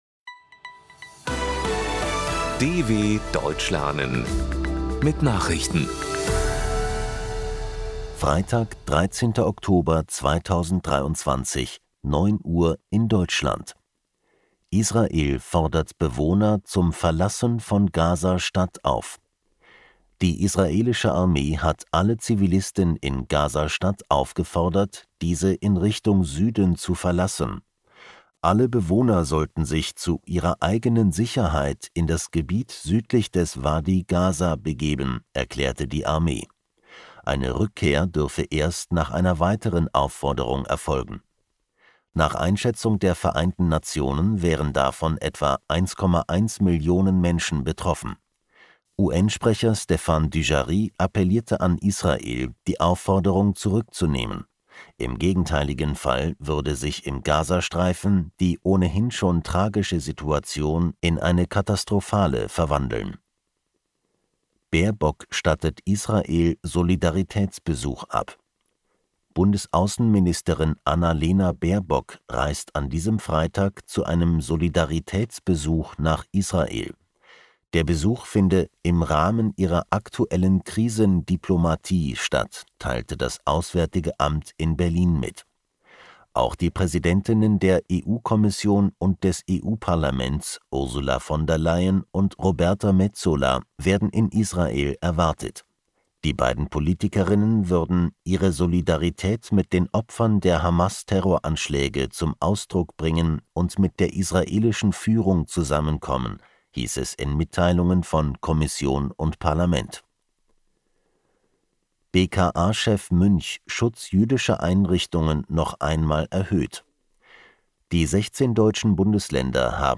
13.10.2023 – Langsam Gesprochene Nachrichten
Trainiere dein Hörverstehen mit den Nachrichten der Deutschen Welle von Freitag – als Text und als verständlich gesprochene Audio-Datei.